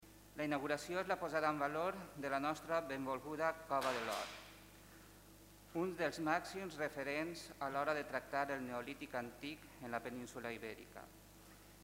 Corte-Alcalde-Beniarrés.mp3